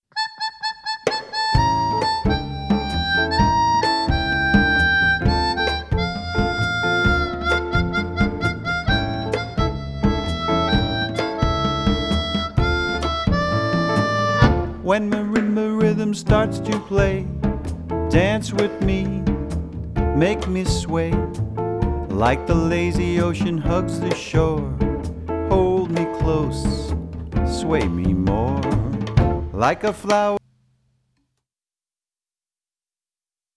New Orleans  Blues